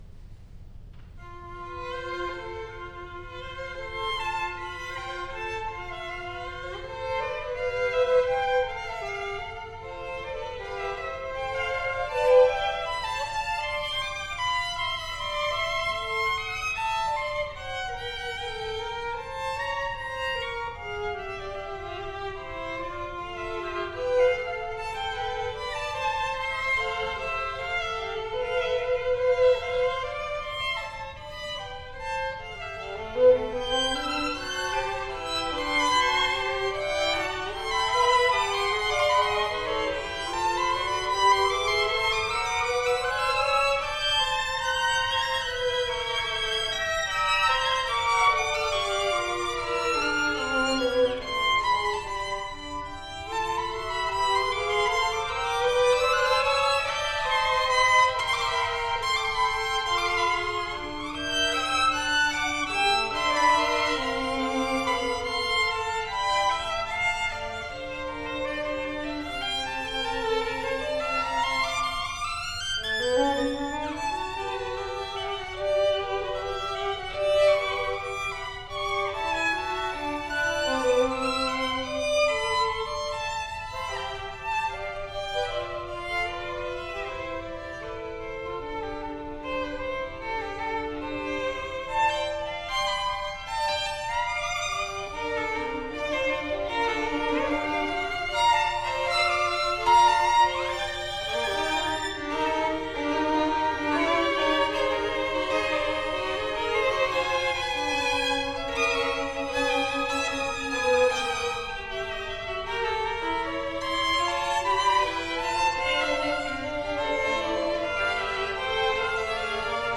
for Four Violins
Recording from Feb. 18, 2014 concert, at Meredith College, Raleigh NC
violins
Adagio  [3:16]
Allegro piccolo jigolo [3:01]
Now the whole piece is tuneful, gentle, and easily appreciated by many ears.